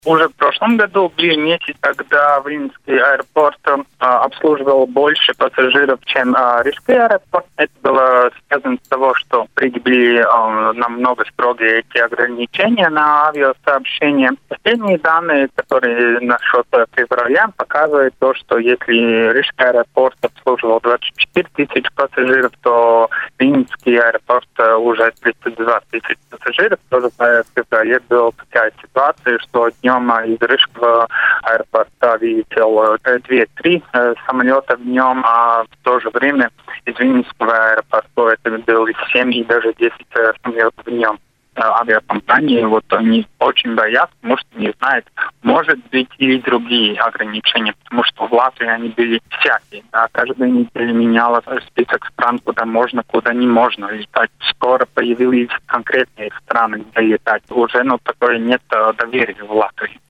Сегодня на радио Baltkom обсуждались такие темы как нетерпимость и ксенофобия в Латвии, будет ли Латвия закупать российскую вакцину, как себя чувствует авиаотрасль в период пандемии.